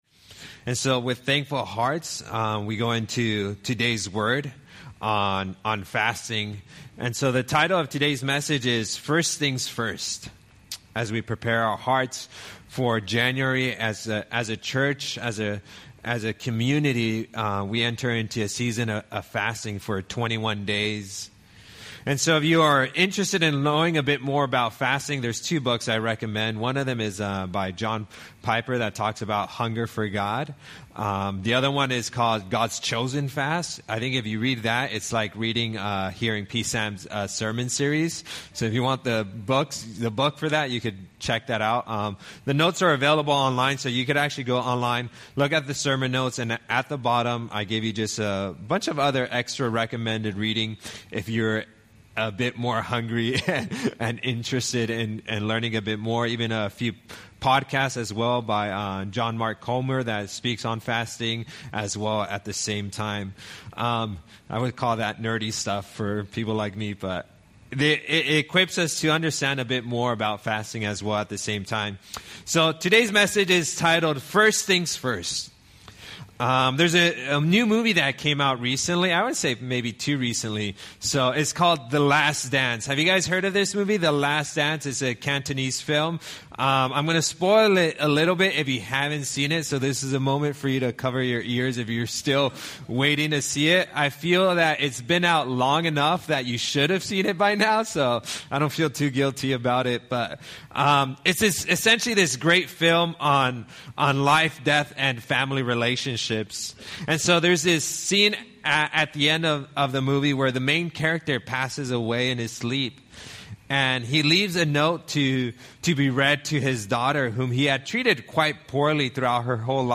Sermons from Solomon's Porch Hong Kong.